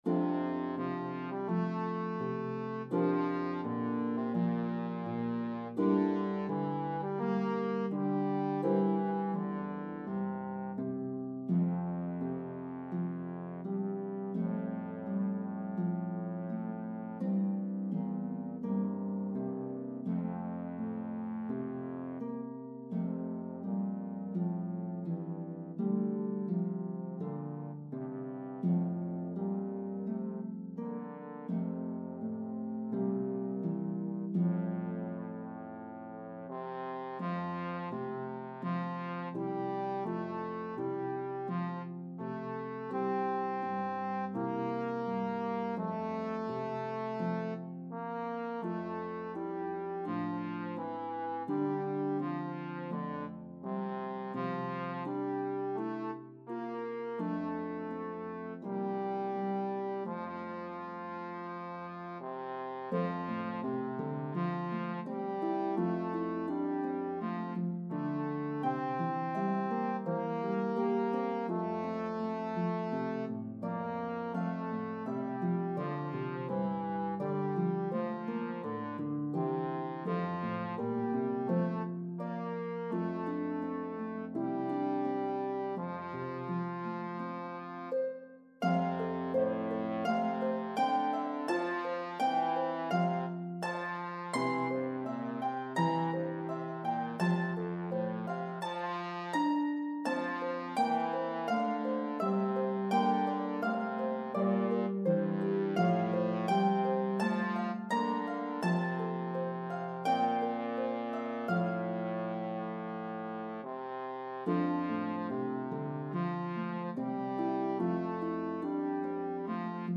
Harp and Trombone version